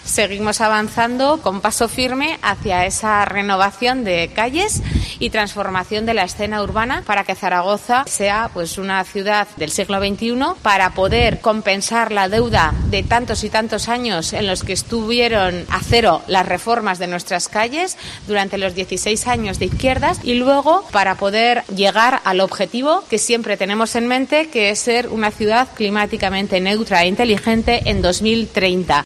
La alcaldesa de Zaragoza habla de la renovación de calles